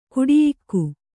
♪ kuḍiyikku